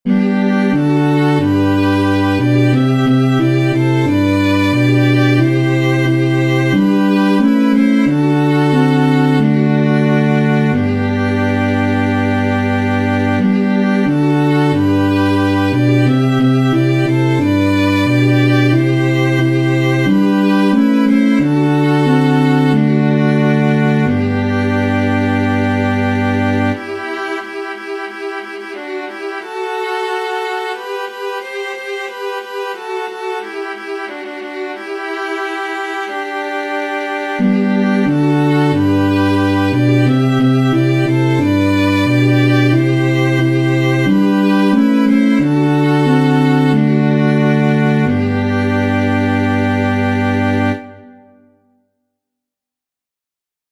Chants d’Acclamations.